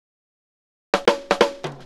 Fill 128 BPM (16).wav